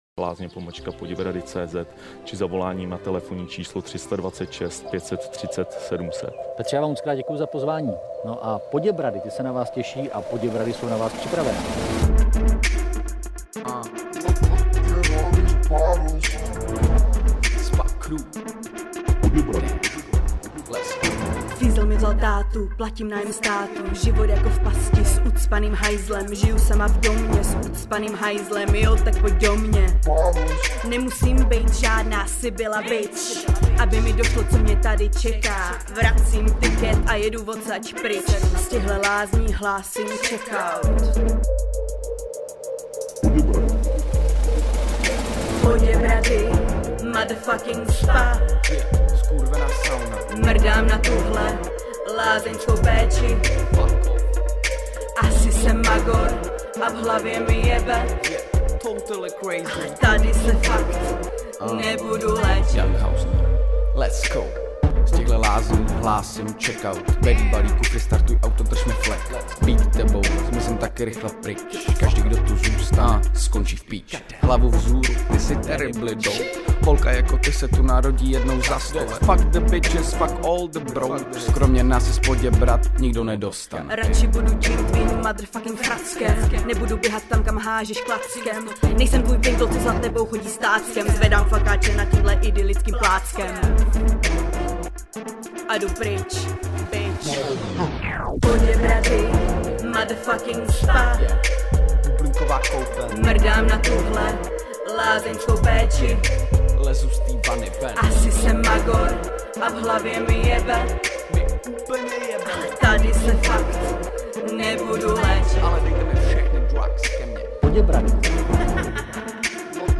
dali to do svýho debilního seriálu a pustili jenom dvacet vteřin, protože se báli že tam jsou sprosťárny. v rapu. lol.
vocals